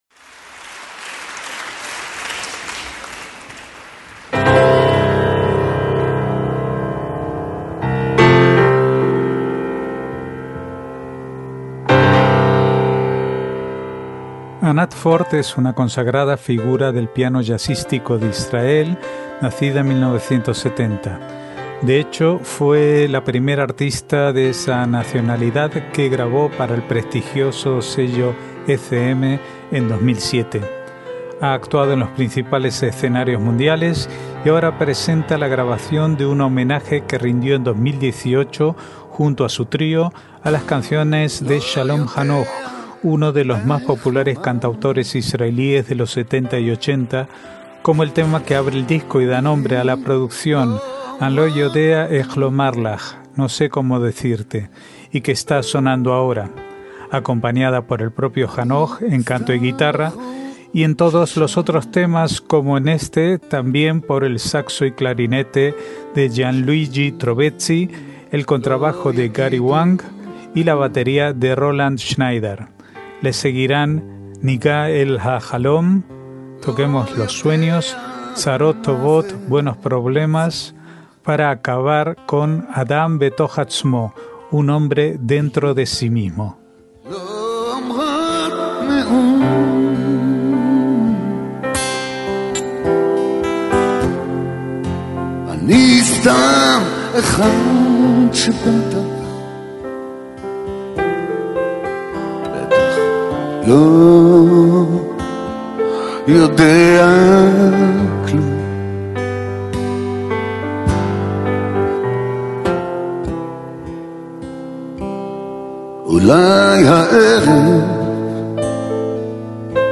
MÚSICA ISRAELÍ
piano jazzístico
canto y guitarra
saxo y clarinete